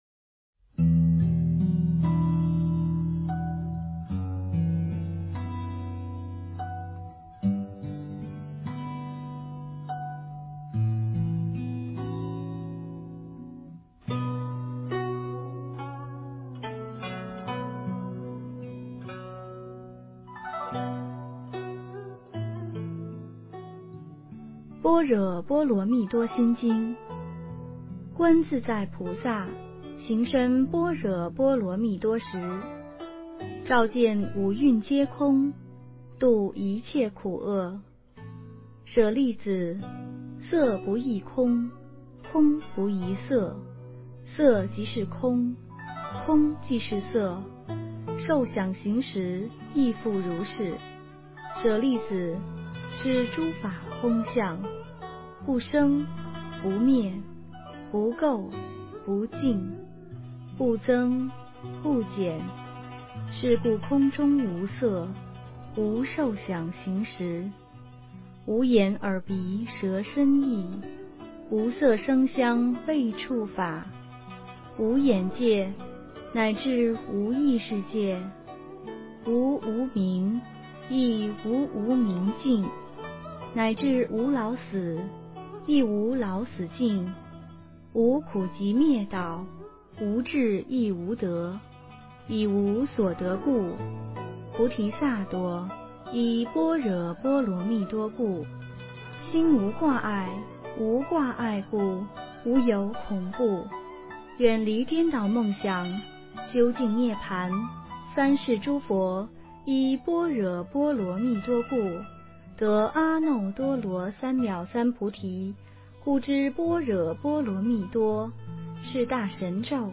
心经(念诵)
诵经 心经(念诵